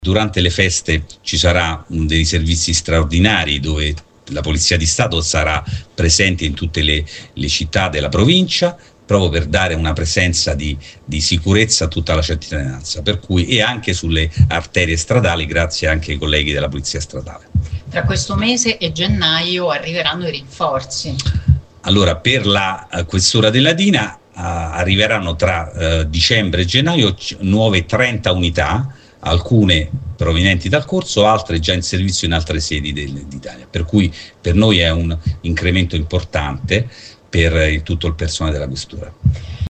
La notizia è arrivata nel corso della conferenza stampa di Natale con cui il Questore ha voluto trasmettere a tutti i cittadini gli auguri di un Sereno Natale.